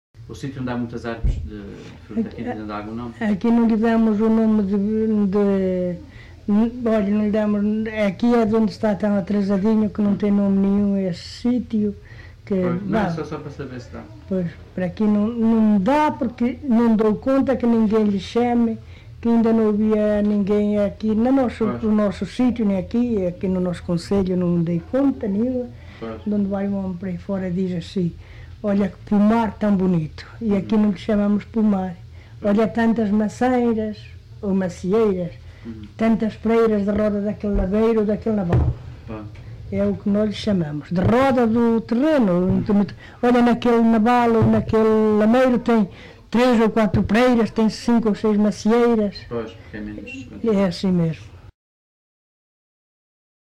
LocalidadeSanto André (Montalegre, Vila Real)